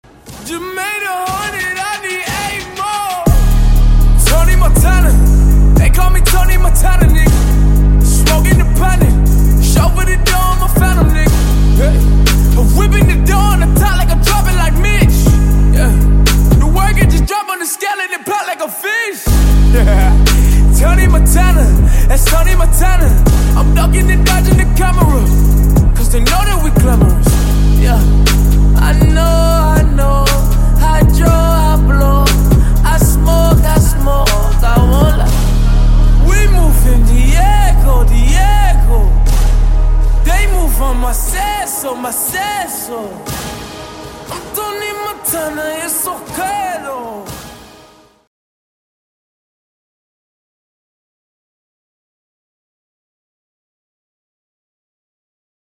рэп рингтоны